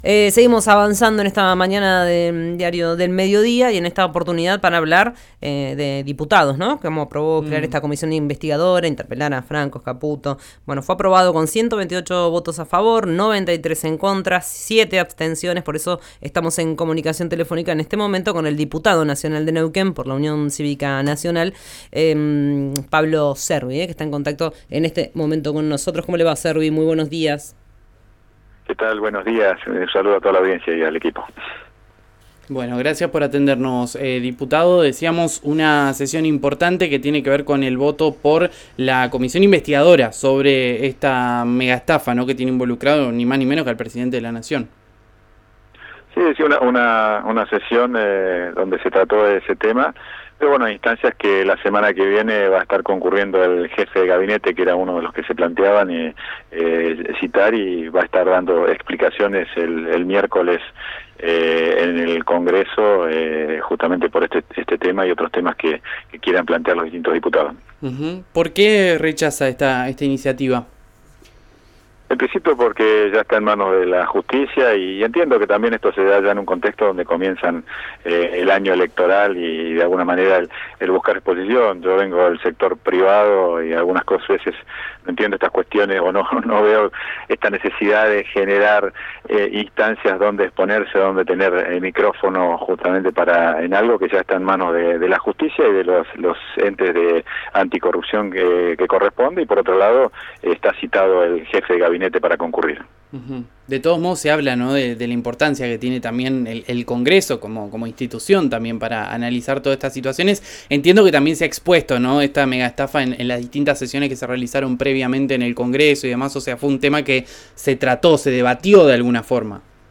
Escuchá a Pablo Cervi, Diputado Nacional de Neuquén por la Unión Cívica Nacional en RÍO NEGRO RADIO: